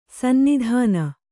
♪ sannidhāna